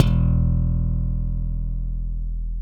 KS 32 SLAP B.wav